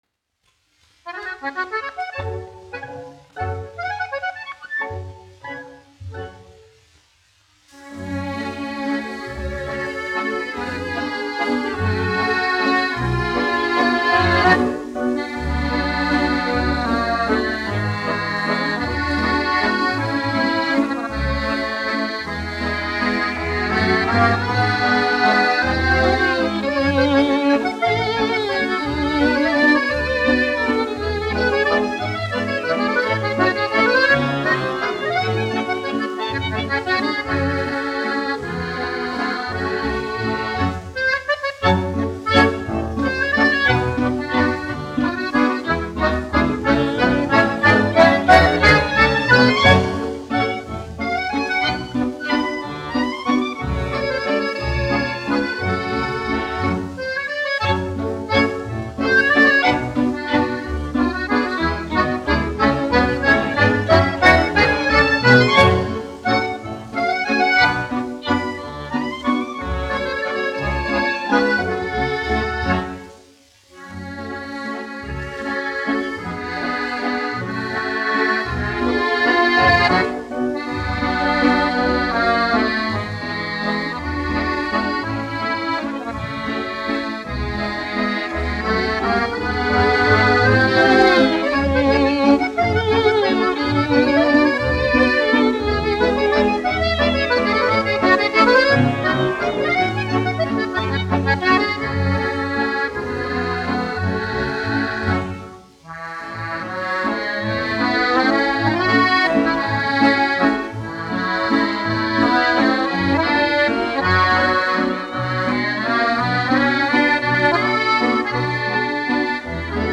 1 skpl. : analogs, 78 apgr/min, mono ; 25 cm
Akordeona mūzika